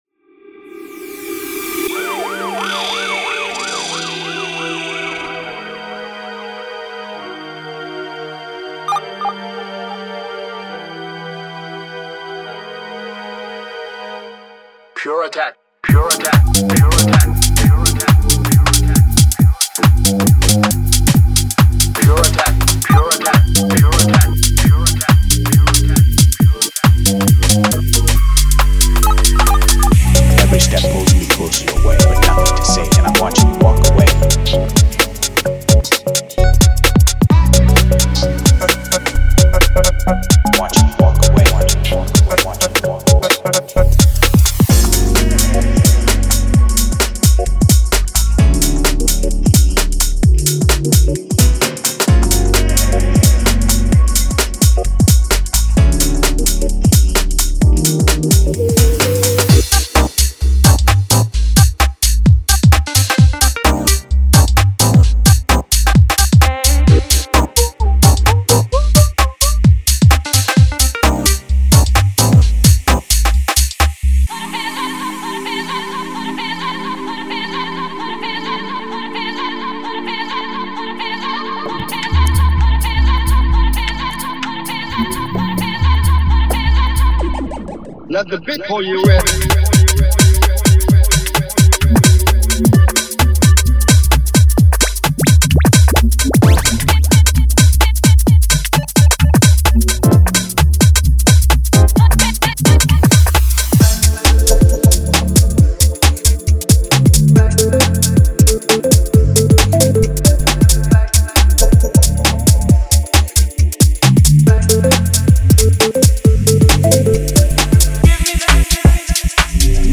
Genre:Garage
デモサウンドはコチラ↓